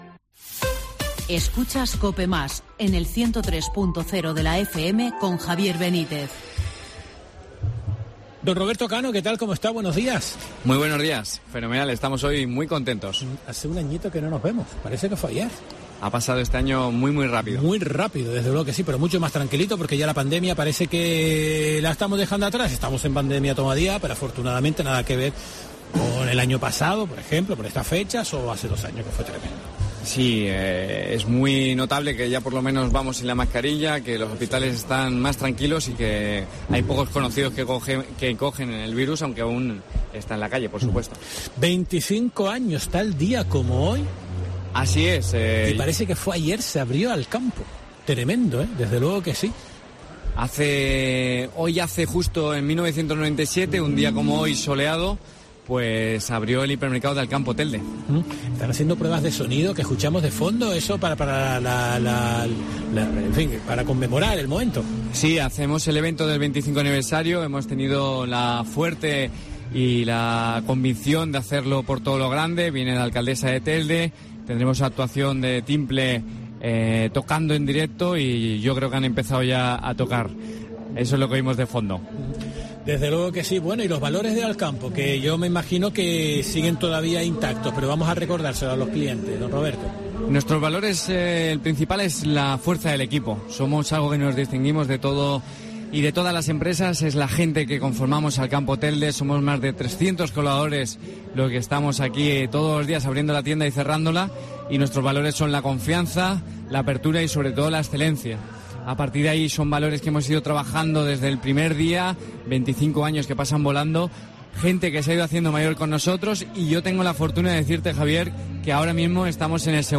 La Mañana de COPE Gran Canaria se desplazó a Alcampo para emitir un especial dirigido a celebrar los 25 años de la compañía en la isla de Gran Canaria, un hecho que aconteció un 9 de diciembre de 1997.